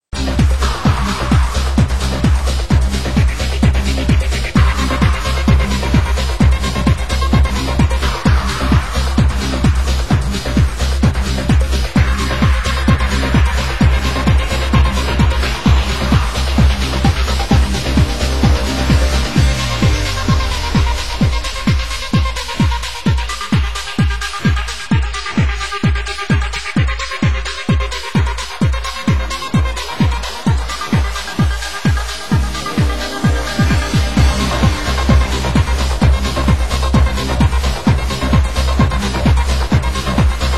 Genre: Trance